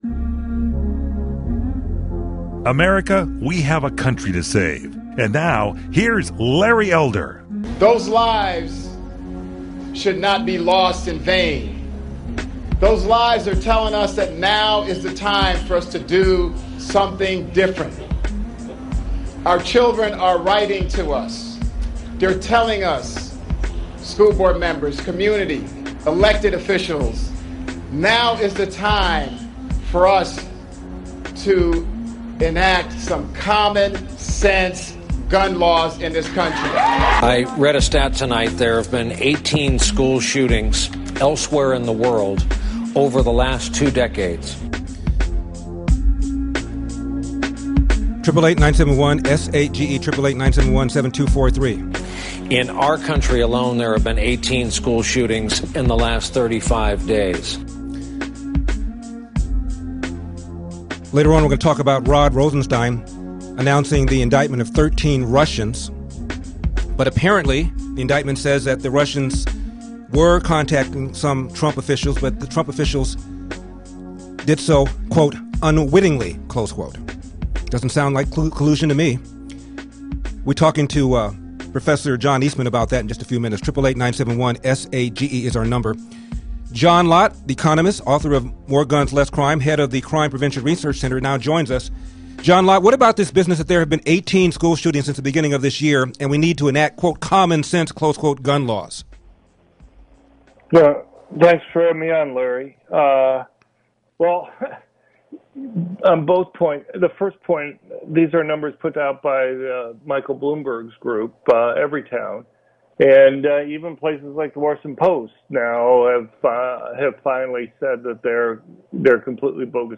media appearance
John Lott talked to Larry Elder on his national radio show about the true nature of gun violence amidst the Left’s calls for more control and regulation.